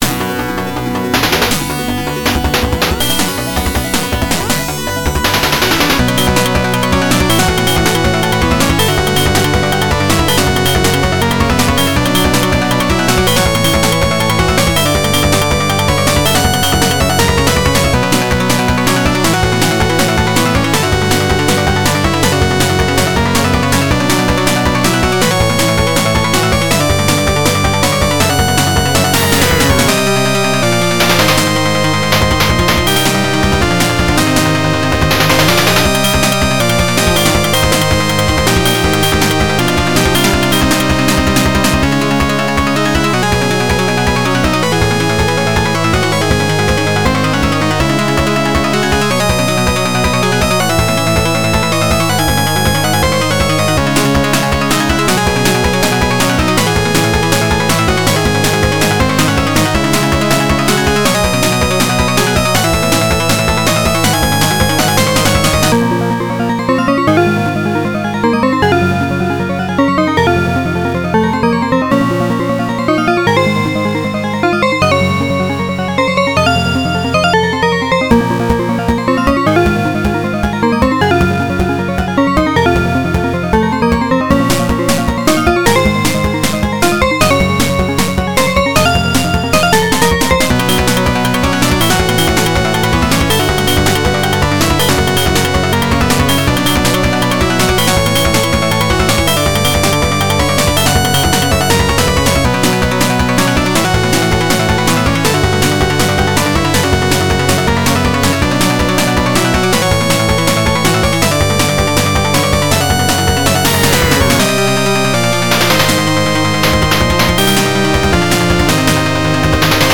原游戏FM26K版，由PMDPlay导出。